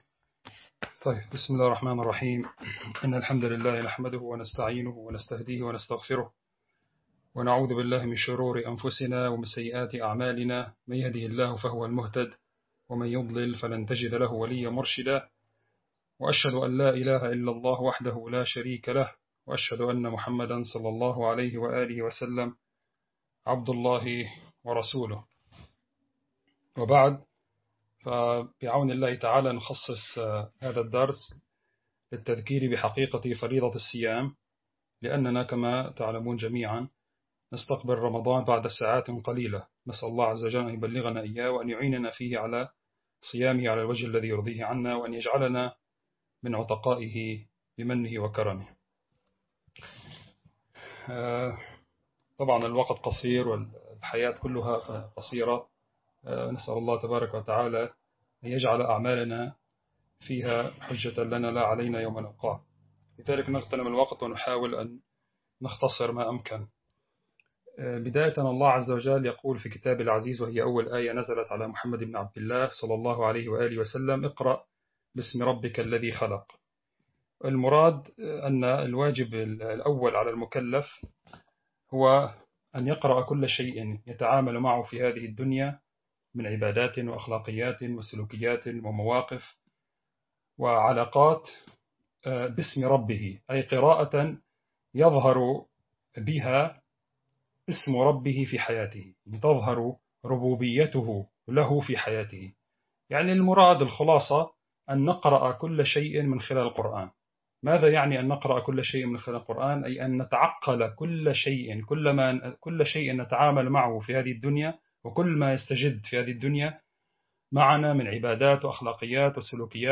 المكان: درس عام online